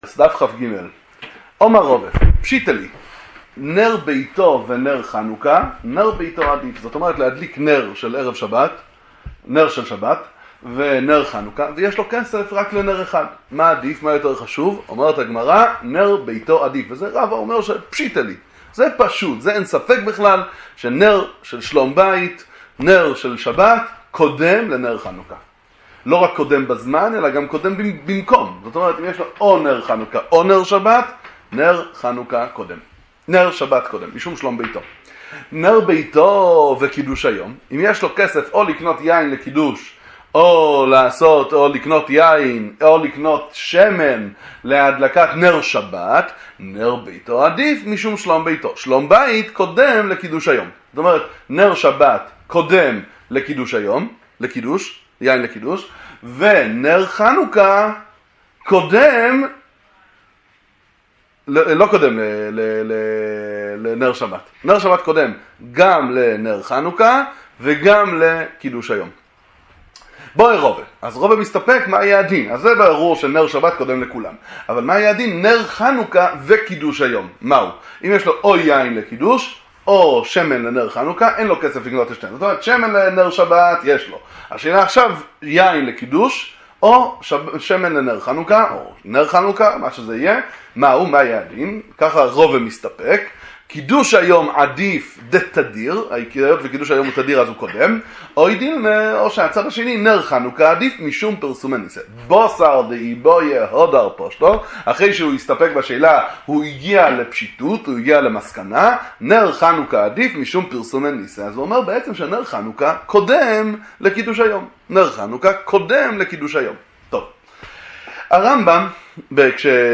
שיעור קצר - בענין מוכר כסותו לנר חנוכה